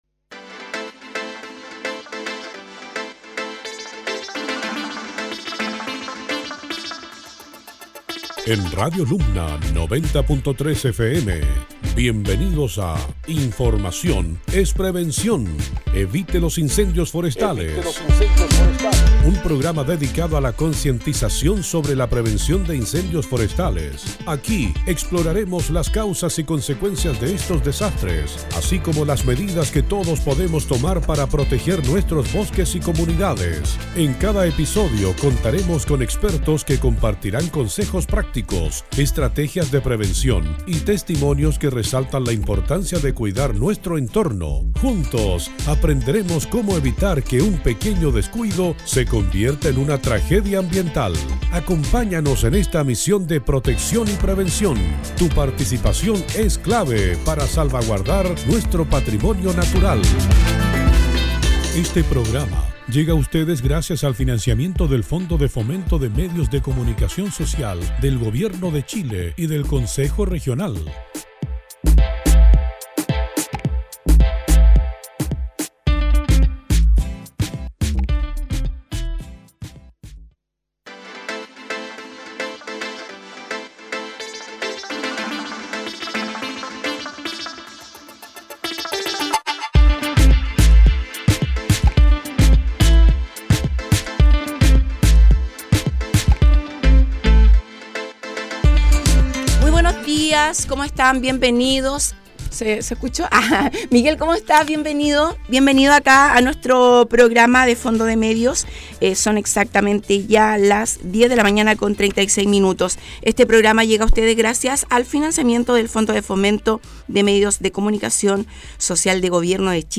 Durante este capítulo tuvimos la oportunidad de entrevistar nuevamente